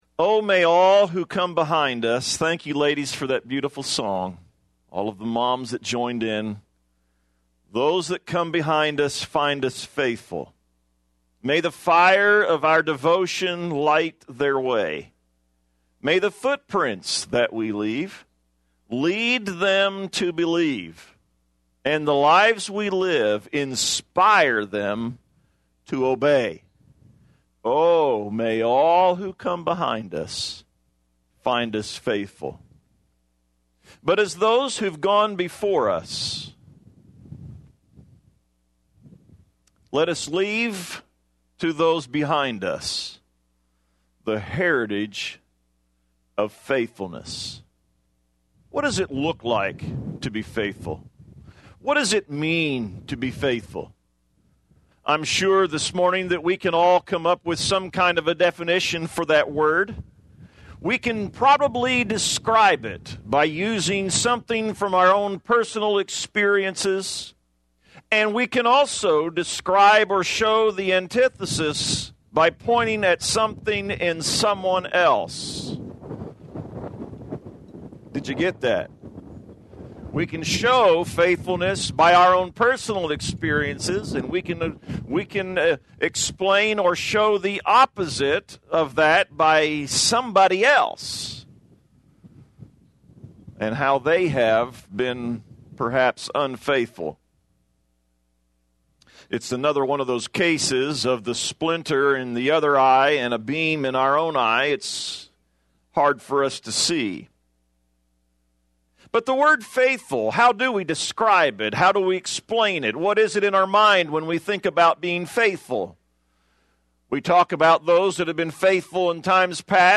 Mother's Day
A sermon